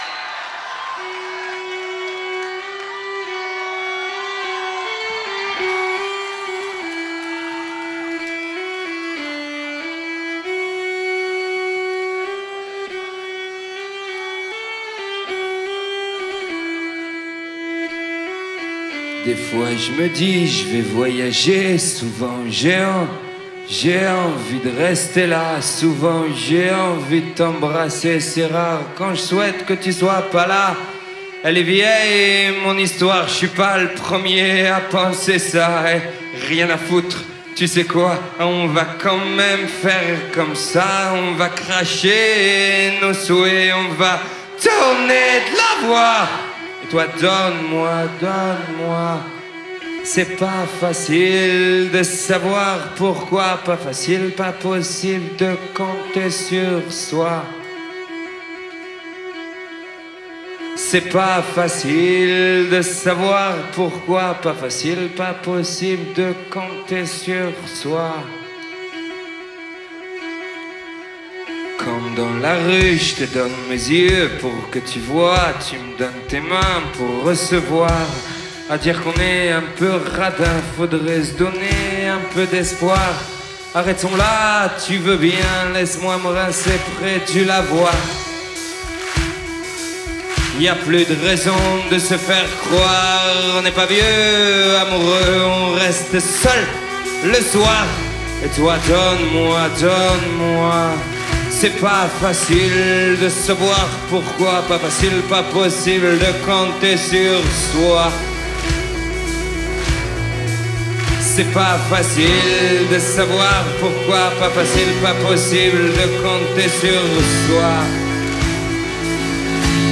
Live à la salle de la Cité, Rennes _ Février 1998